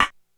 Rimshot.wav